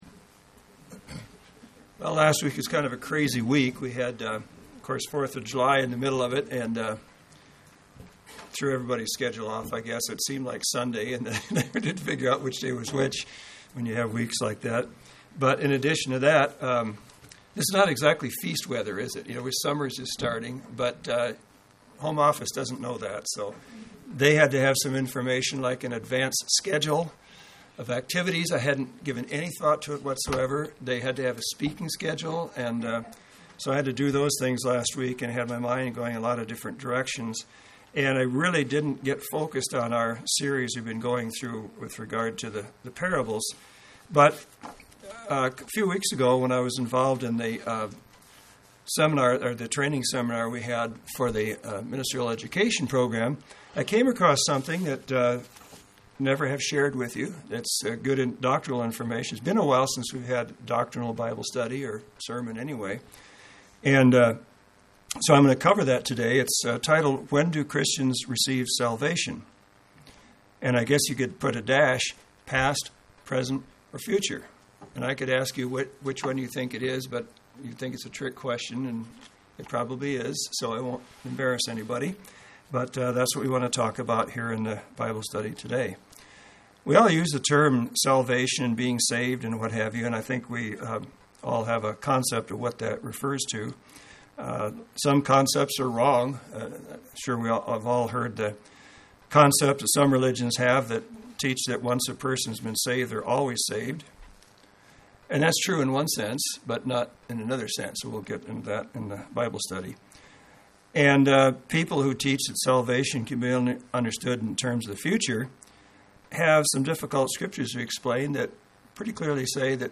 An in-depth Bible study that answers the question of when Christians receive salvation.
Given in Central Oregon
UCG Sermon Studying the bible?